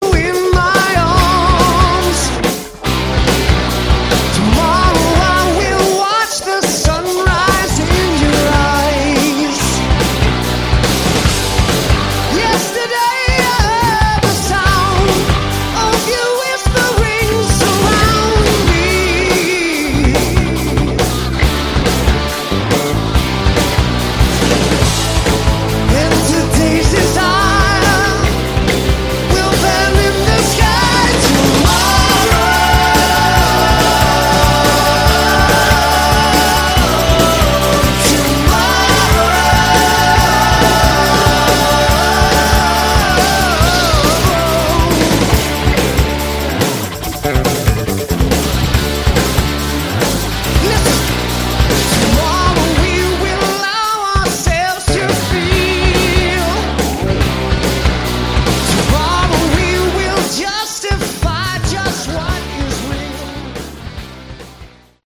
Category: AOR
vocals, guitars
lead guitars, guitars, background vocals
bass, background vocals
drums, percussion, background vocals